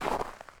footstep_snow4.ogg